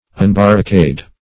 Search Result for " unbarricade" : The Collaborative International Dictionary of English v.0.48: Unbarricade \Un*bar`ri*cade"\, v. t. [1st pref. un- + barricade.]